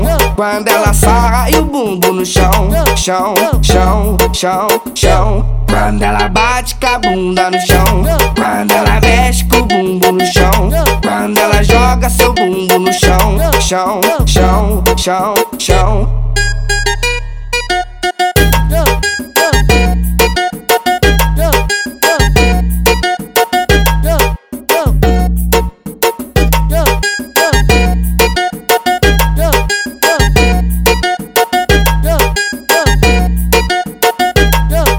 Жанр: Фанк
# Baile Funk